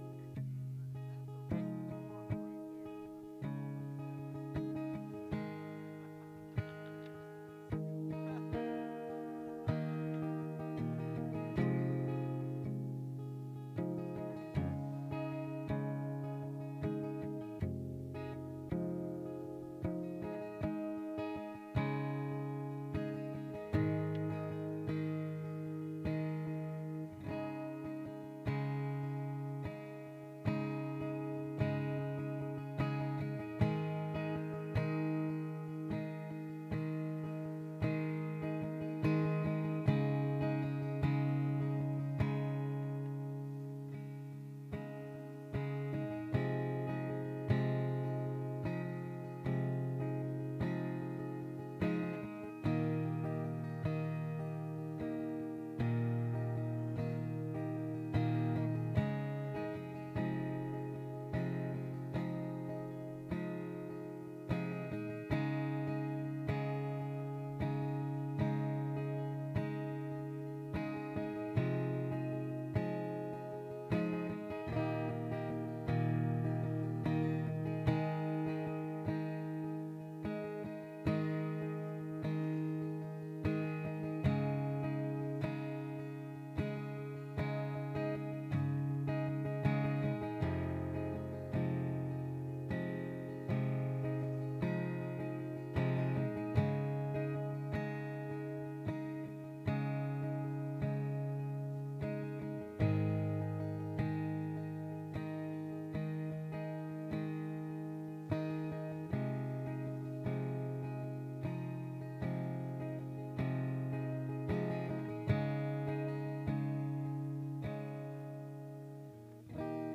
SERMON DESCRIPTION Family is an important part of our life.